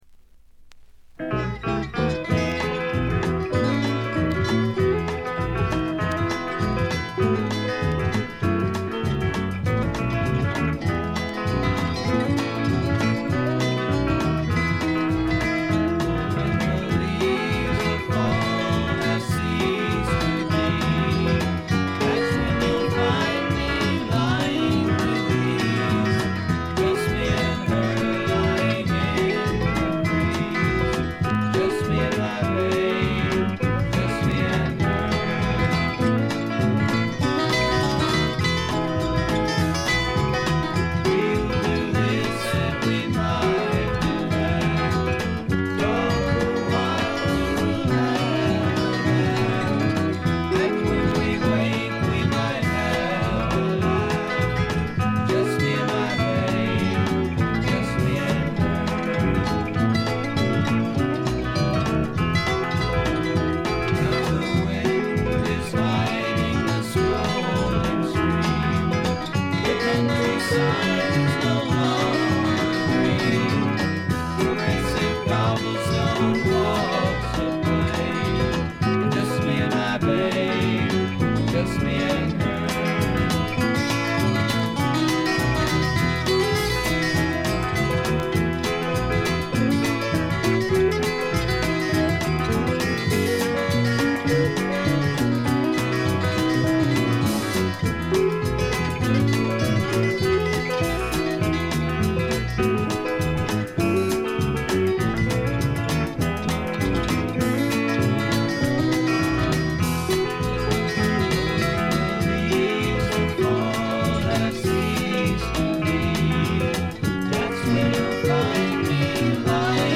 静音部での軽微なバックグラウンドノイズ程度。
素晴らしいサイケデリック名盤です。
試聴曲は現品からの取り込み音源です。
Recorded At - Sound City Inc, Recording Studios